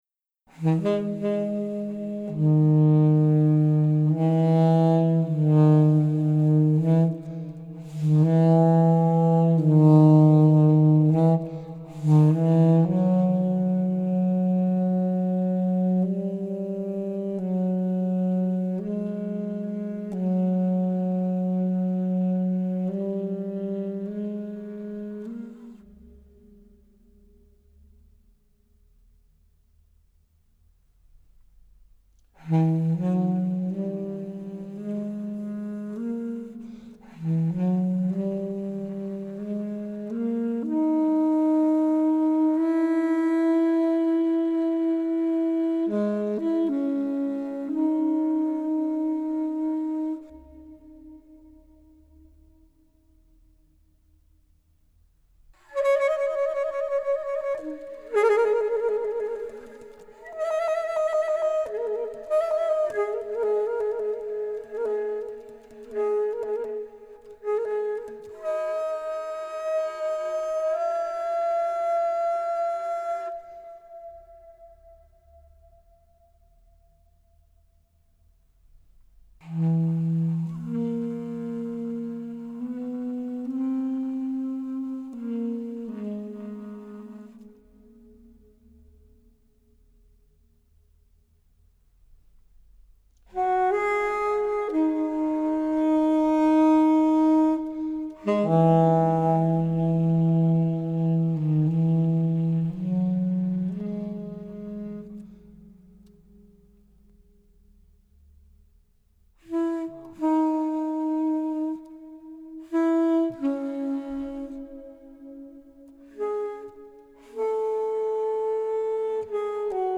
alto saxophone, piano